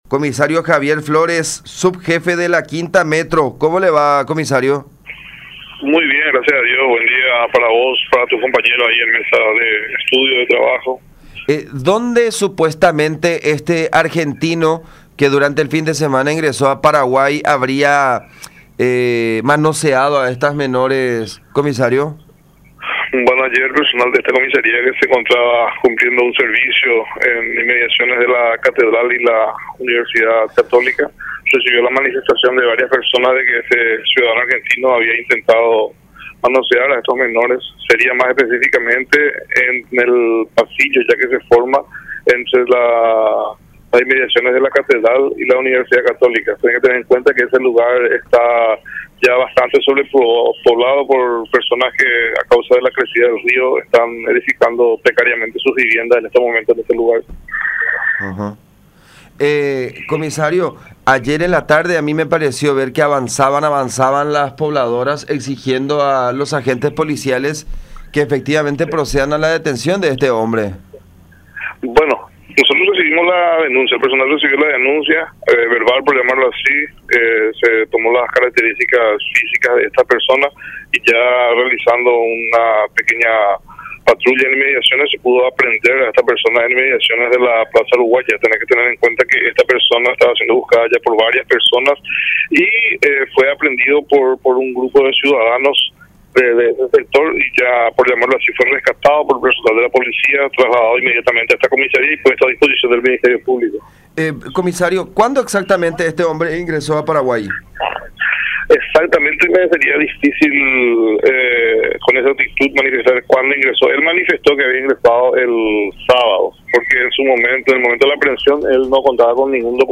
“Según los denunciantes, no abusó de los menores, pero los manoseó”, expuso el uniformado en contacto con La Unión.